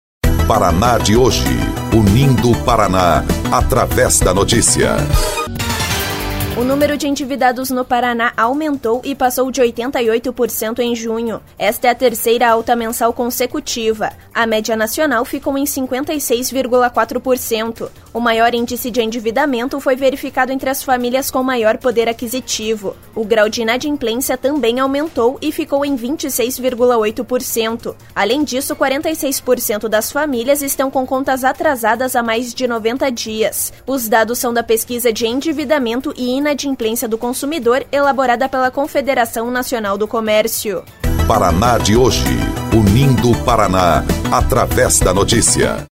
BOLETIM – 88,7% dos paranaenses estão endividados